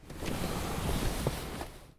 exit vehicle.wav